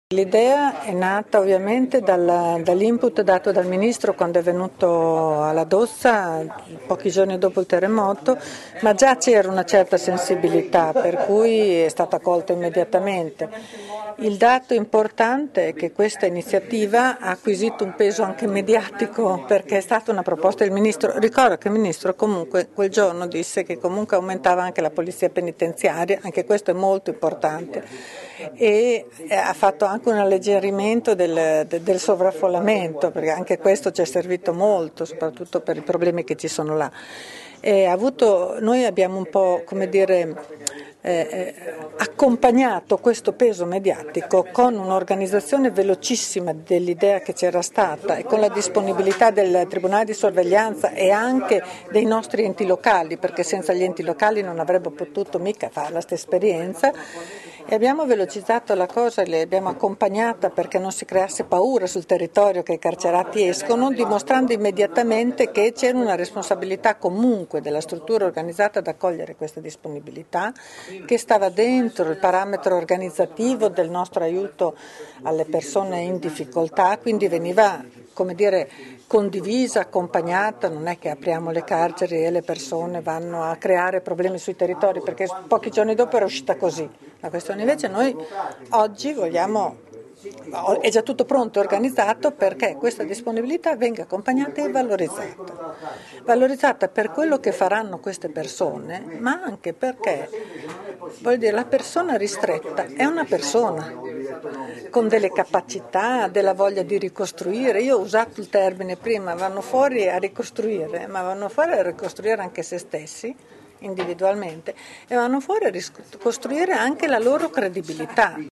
Tra i criteri di selezione dei detenuti volontari anche le loro abilità; il progetto infatti mira ad una duplice ricostruzione, quella delle aree danneggiate ma anche quella dei detenuti stessi che, impegnandosi nella ricostruzione, “vanno fuori a ricostruire, ma vanno fuori a ricostruire anche la loro credibilità“, come ha detto l’assessore regionale alle Politiche sociali Teresa Marzocchi.